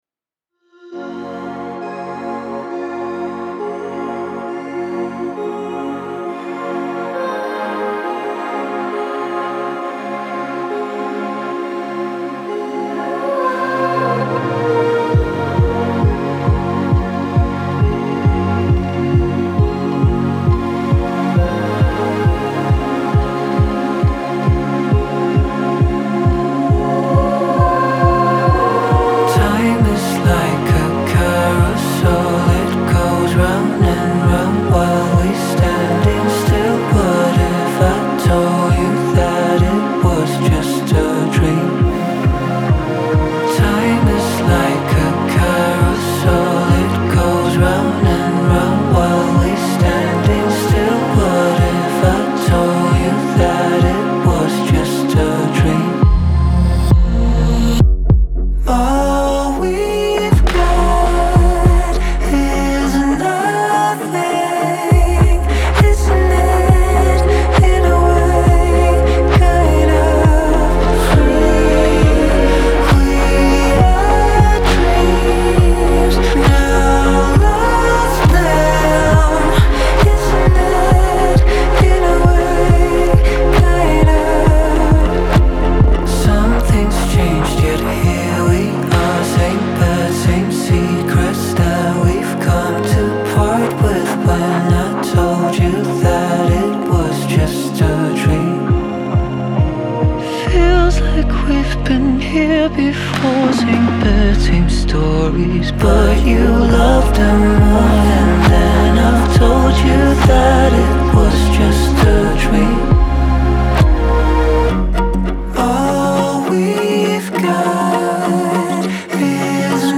это атмосферная композиция в жанре электроник и синт-поп